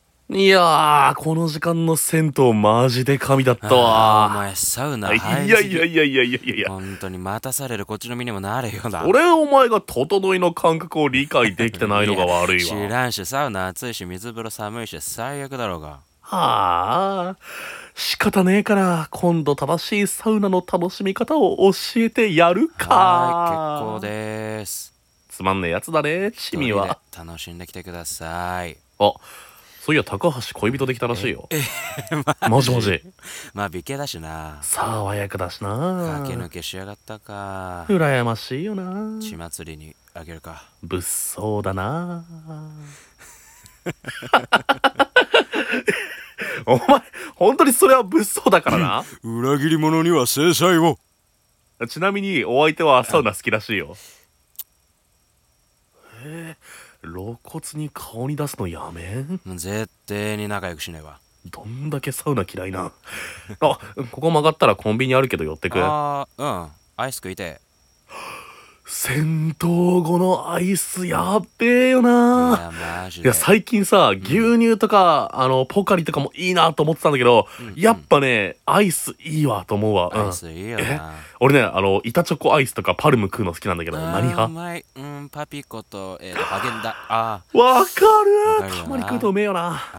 2人声劇 「何気ない日常会話」